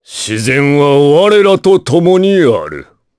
Kaulah-Vox_Victory_jp.wav